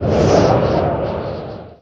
windgust1.wav